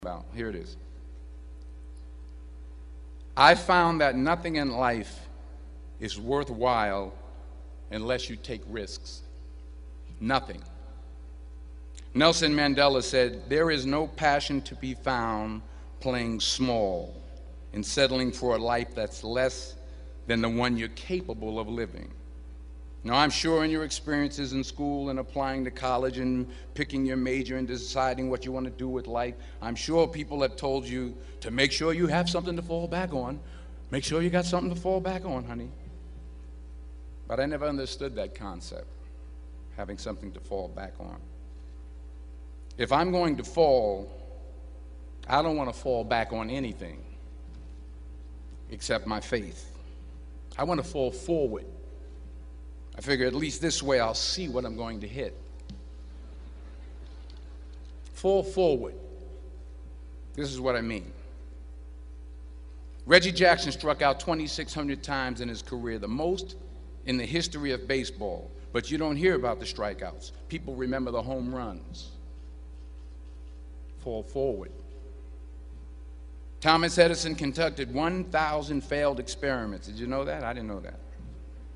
公众人物毕业演讲第422期:丹泽尔2011宾夕法尼亚大学(6) 听力文件下载—在线英语听力室